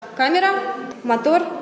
Звуки кинохлопушки
Звук и камерная съемка актерской игры